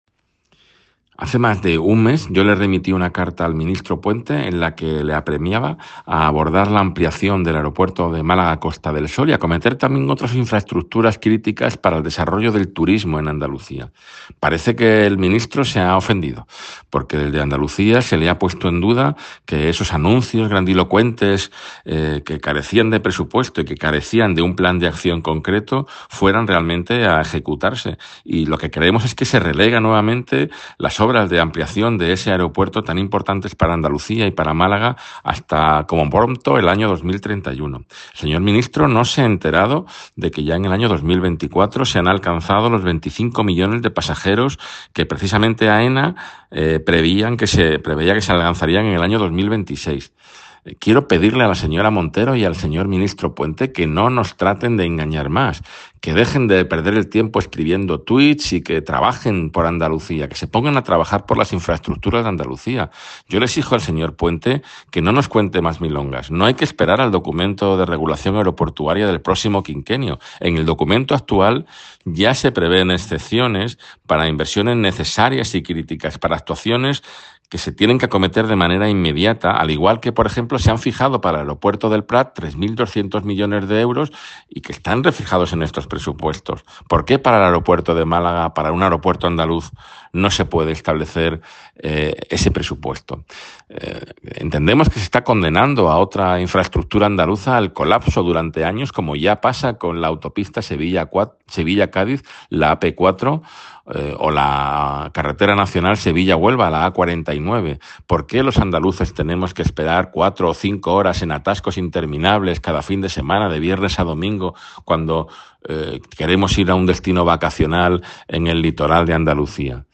El consejero de Turismo y Andalucía Exterior de la Junta de Andalucía, Arturo Bernal, ha vuelto a reclamar al Gobierno de España el impulso con inversiones reales de las infraestructuras pendientes para la conectividad turística en la comunidad, entre las que se encuentra el Aeropuerto de Málaga.
Así lo ha señalado desde Málaga en el foro 'El arte de viajar bien: donde el turismo se convierte en experiencia', organizado por El Confidencial.